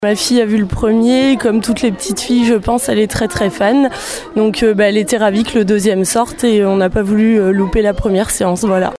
La preuve hier matin au cinéma Le Palace de Surgères qui a accueilli beaucoup de monde pour la première séance à 10h30. Une première que n’aurait surtout pas voulu manquer cette mère de famille :